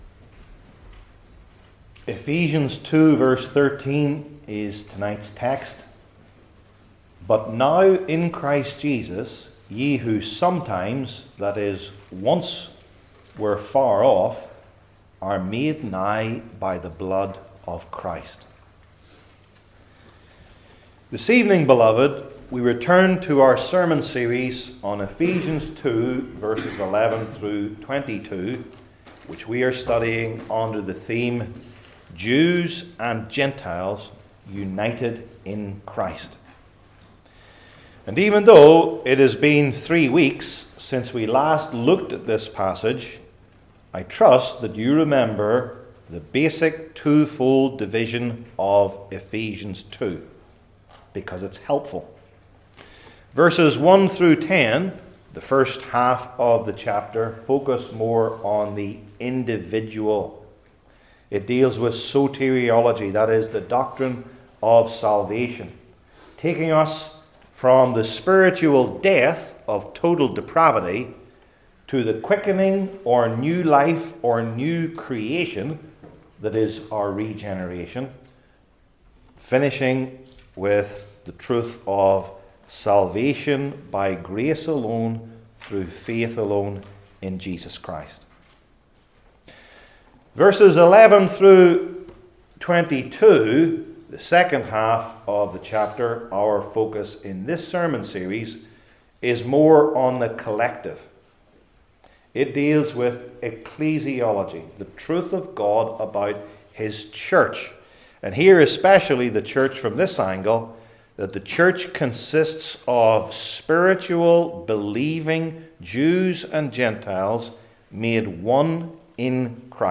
Ephesians 2:13 Service Type: New Testament Sermon Series I. The Former Situation II.